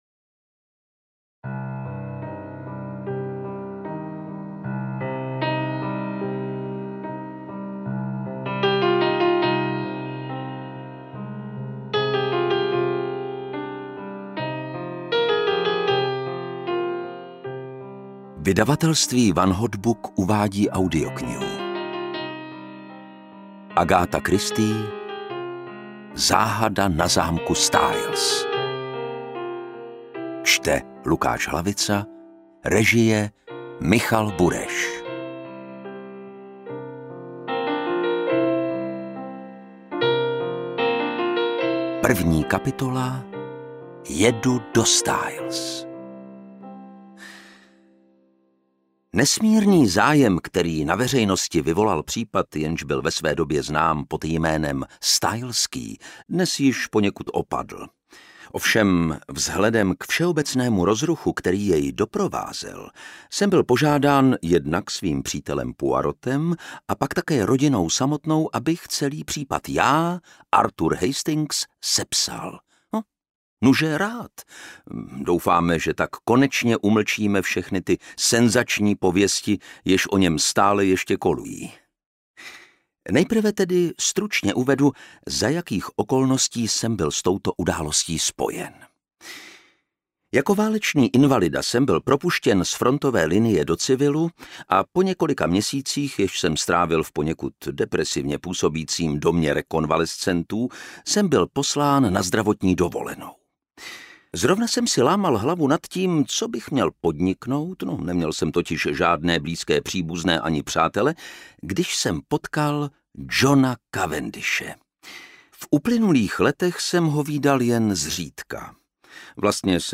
Interpret:  Lukáš Hlavica